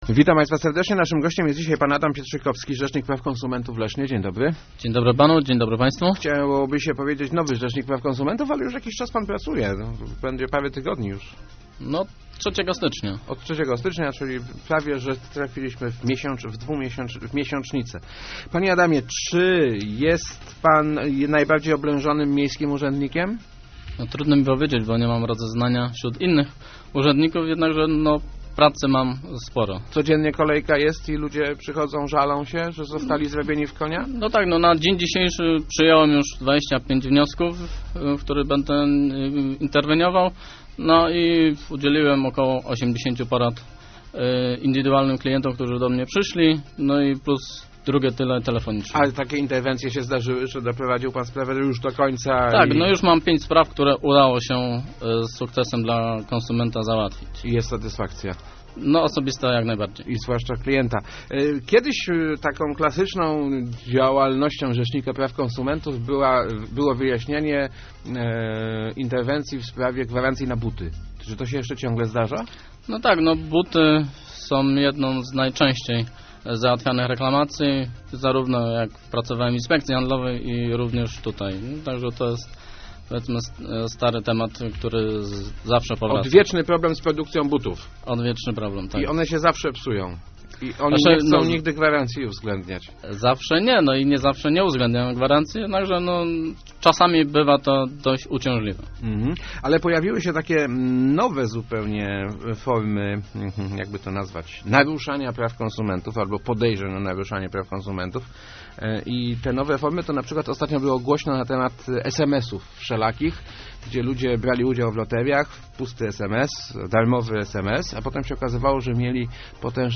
Uważajmy co podpisujemy - mówił w Rozmowach Elki Adam Pietrzykowski, Miejski Rzecznik Praw Konsumentów w Lesznie.